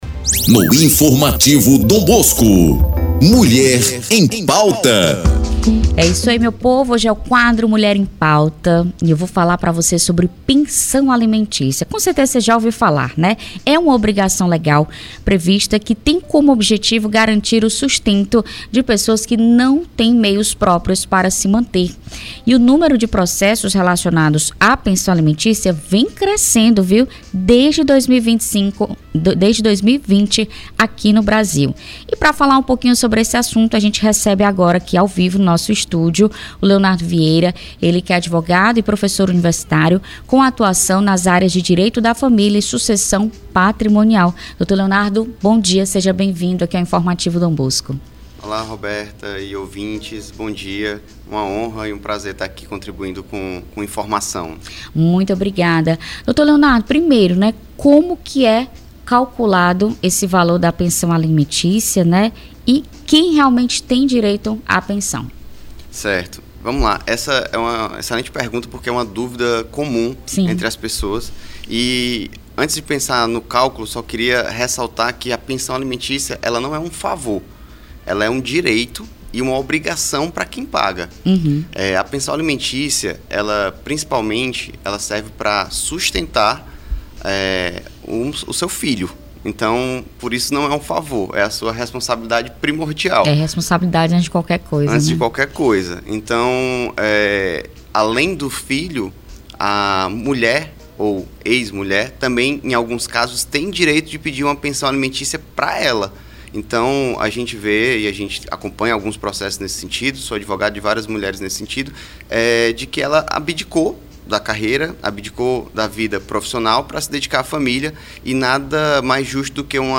Especialista explica direitos de mães e filhos na pensão alimentícia; confira entrevista